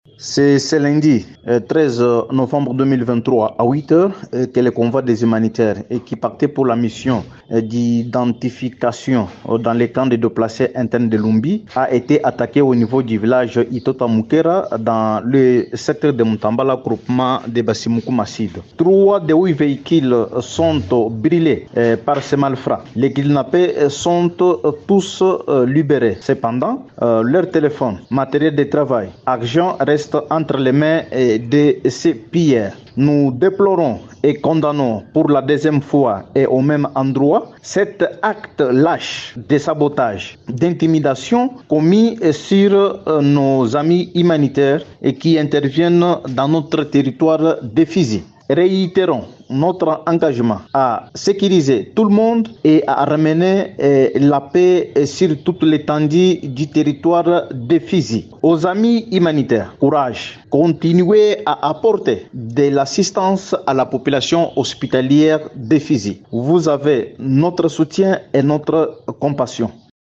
Samy Kalonji Badibanga revient sur cet incident :